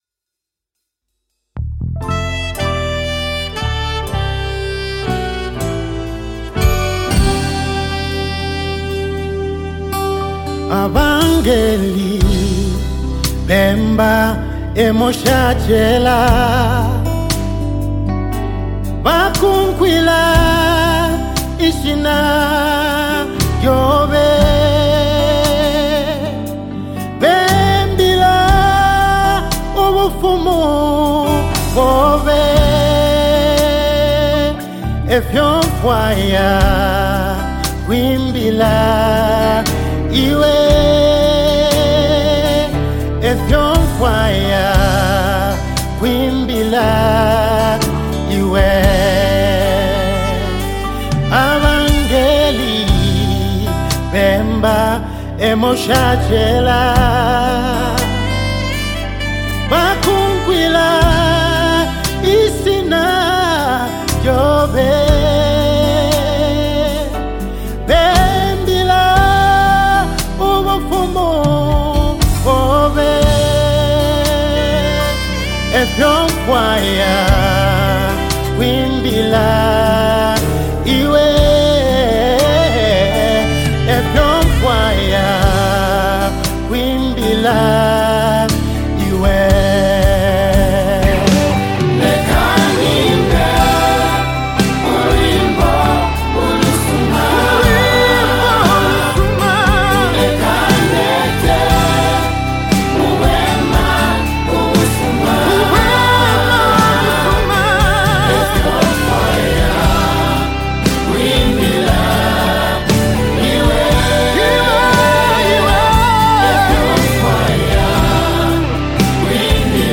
Gospel Music
a smooth melody